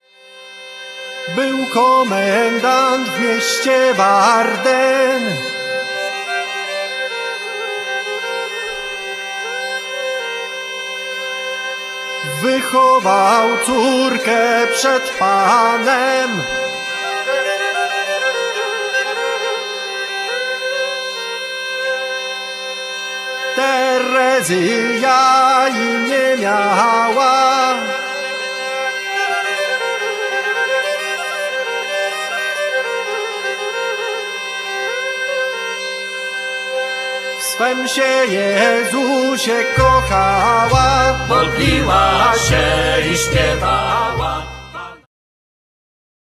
skrzypce, mazanki
piszczałki
kontrabas
obój
saz, oud, lira korbowa, mandola
instrumenty perkusyjne, koboz